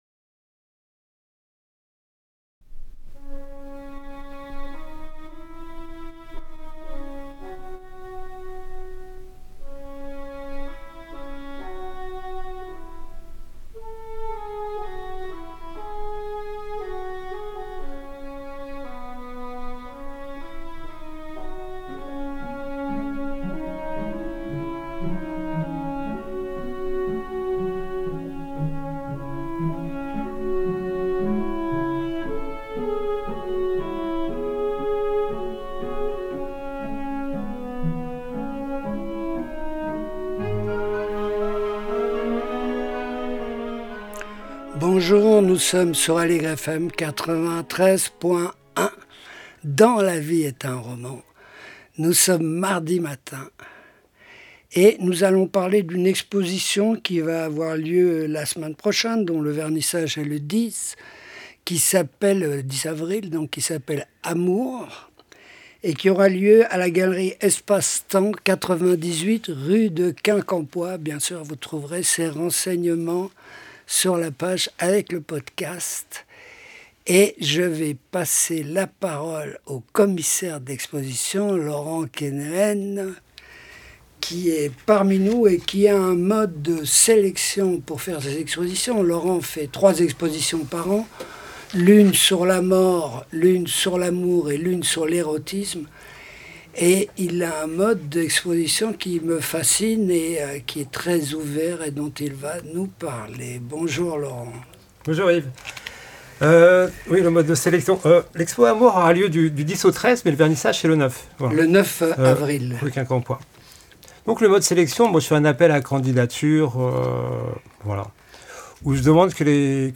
Discussion avec Catherine Millet